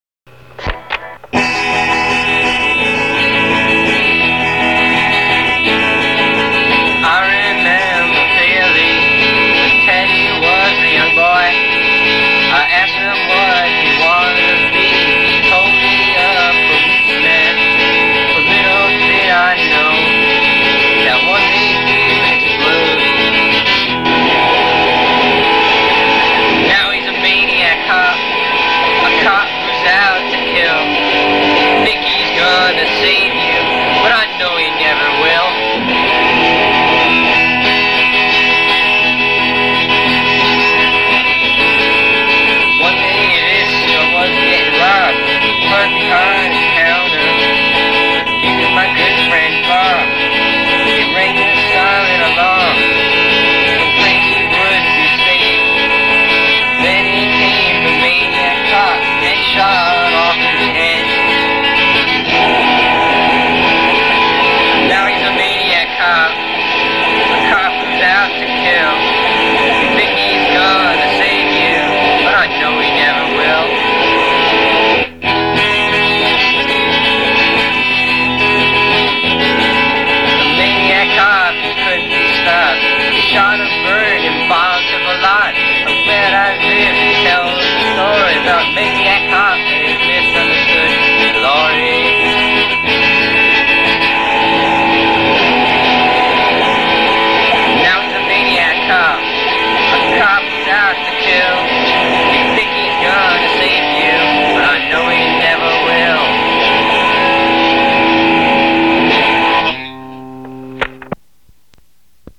Instruments used: Korg Triton : Drums, industrial sounds Minimoog: Sweeps, arpeggiation, additional factory sounds, sync solo at end Hammond XM-1 at end Roland MVS-1: Poly lead, bass synthesizer Epiphone Thunderbird Gothic Voice processing done using an Electroharmonix Small Clone and a Zoom RFX-2000 to provide vocoding. Recording was done on an MSR-24 1" 24-track machine. with the vocals and bass first recorded on an 8-track TSR-8 and bounced. Mixing was done to a Studer A807. Things which could be improved include the line 'Robots of my own design' which seems to have gone flat and may need to be rerecorded. I cranked the bass up too high at the end. In addition, the master tape seems to have been bent out of shape and may also have been slit incorrectly, leading it drift vertically on occasion and causing slight dropouts.